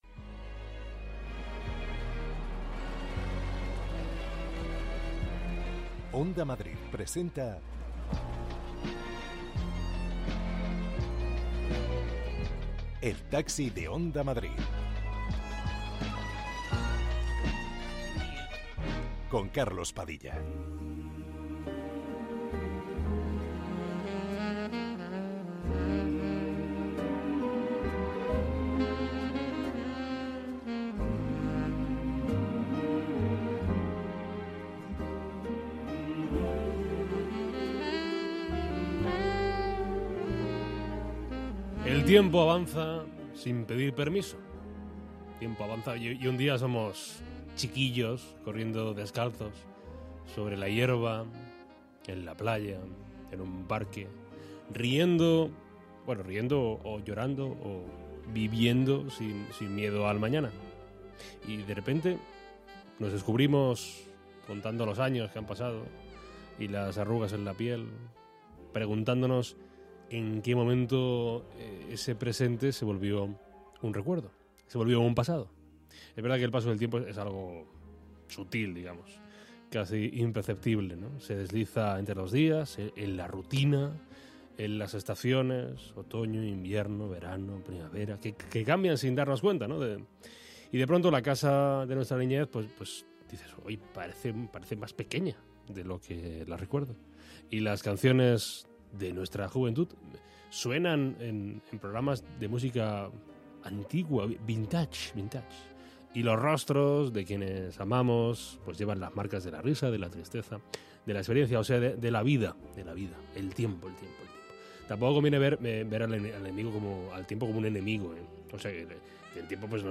Conversaciones para escapar del ruido.